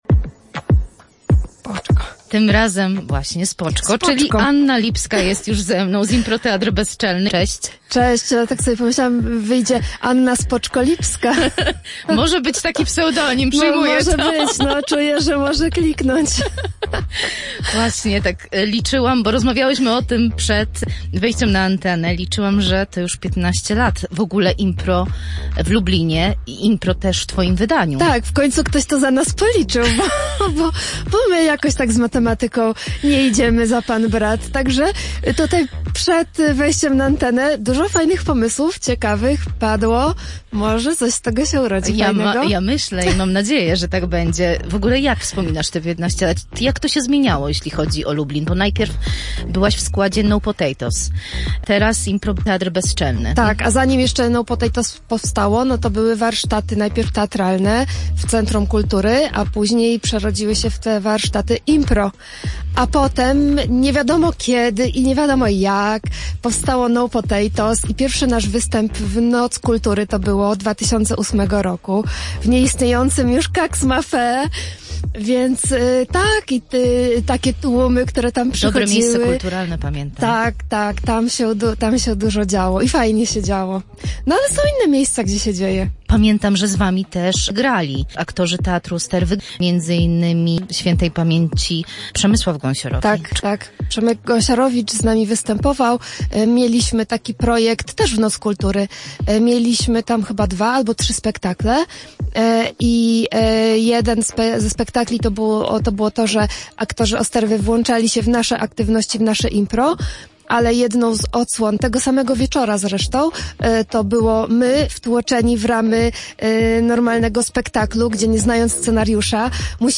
Polecam naszą rozmowę i czekam na obchody: